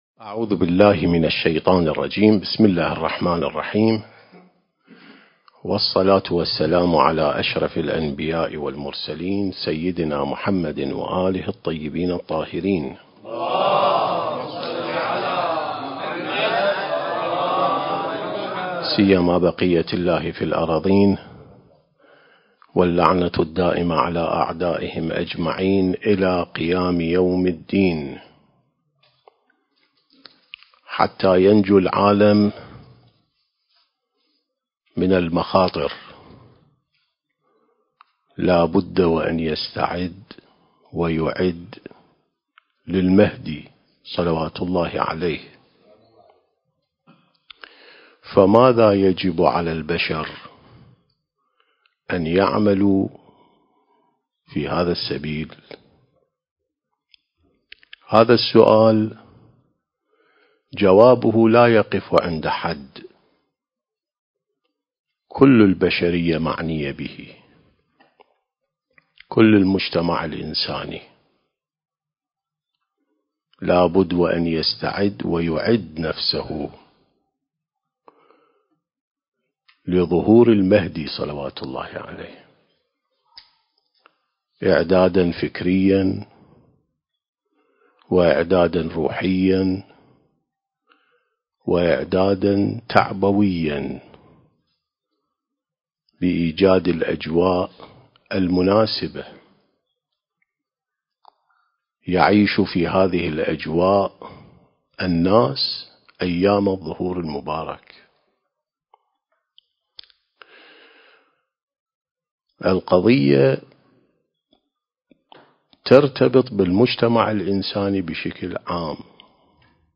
سلسة محاضرات الإعداد للمهدي (عجّل الله فرجه) (5) التاريخ: 1444 للهجرة